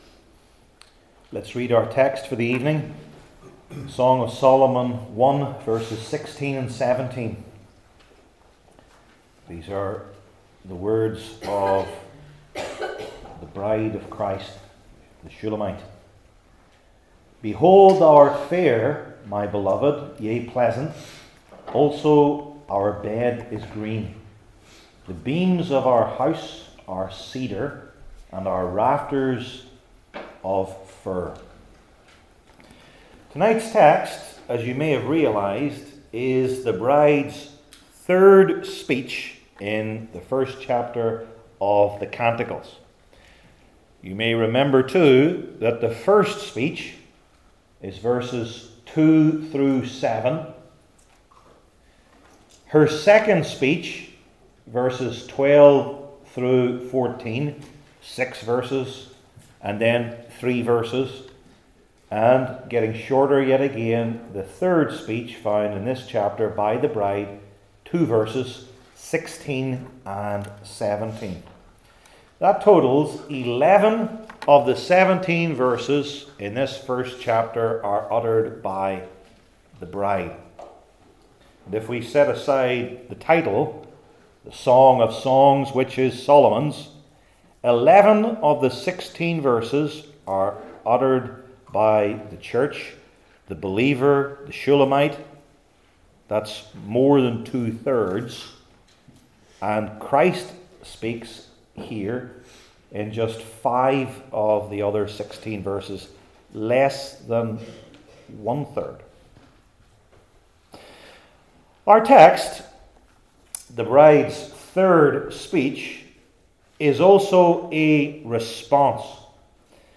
The Greatest Song Ever Passage: Song of Solomon 1:16-17 Service Type: Old Testament Sermon Series I. Christ’s Beauty II.